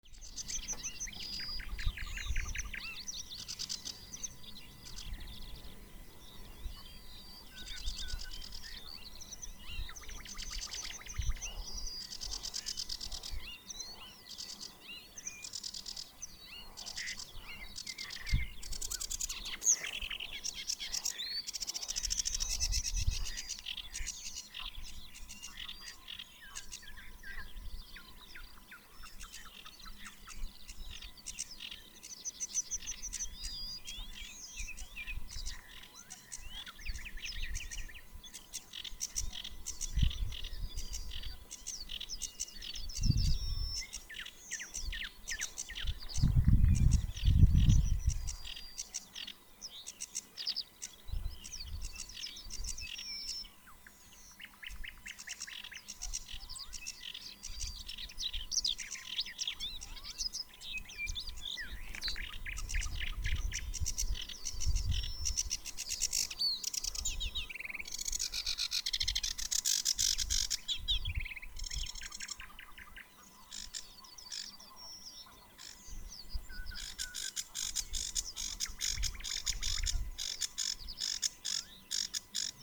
Ceru ķauķis, Acrocephalus schoenobaenus
StatussDzied ligzdošanai piemērotā biotopā (D)